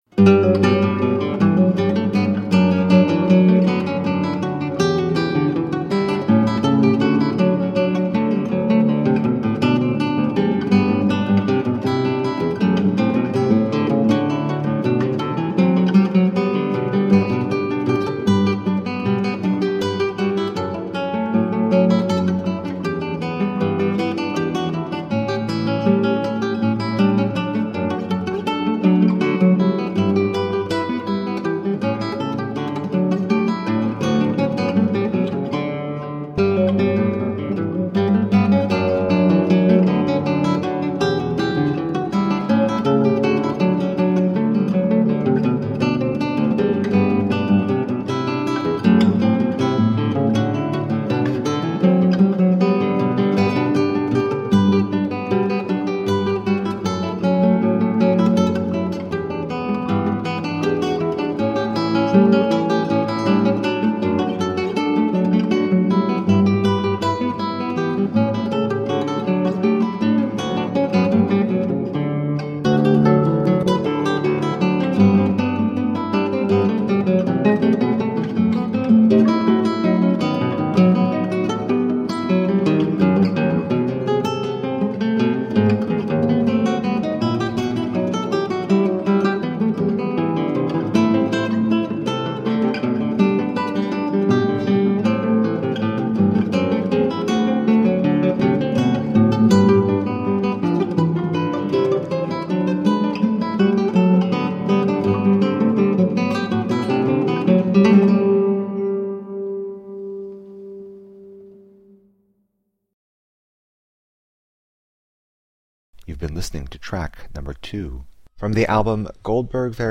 Elegant classical guitar.